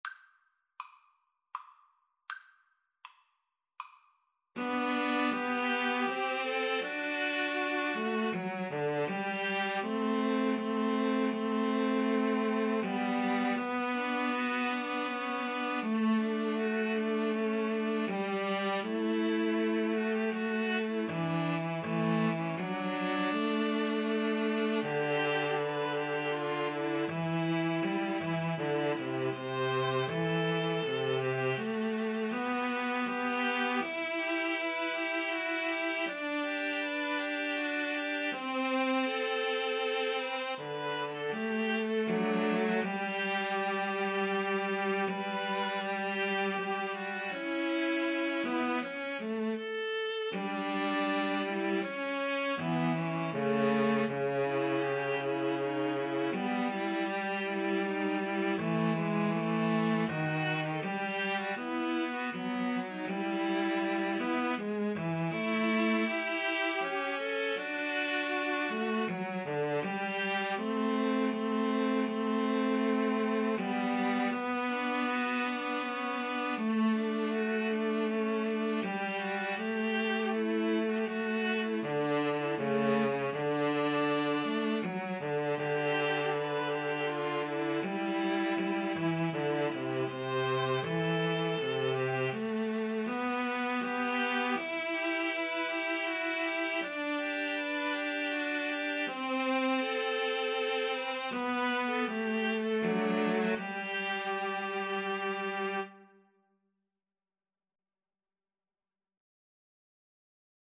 Andante
Classical (View more Classical String trio Music)